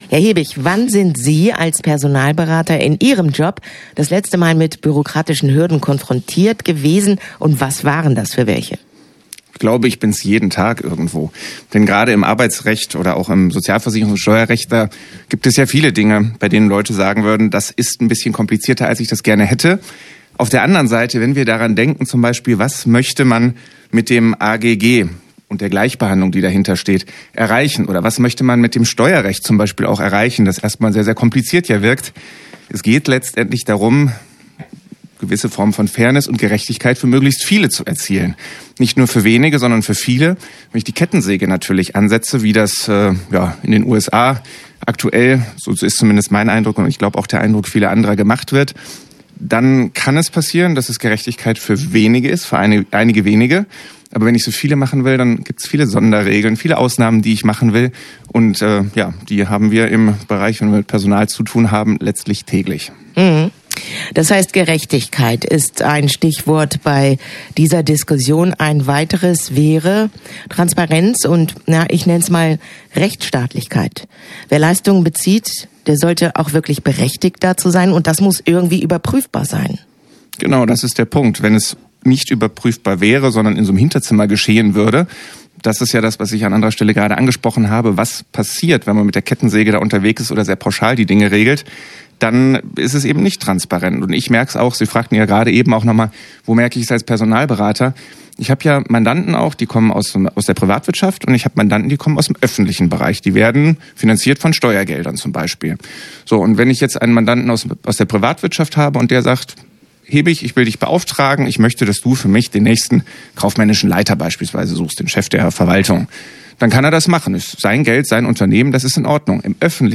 im Gespräch - Okerwelle 104.6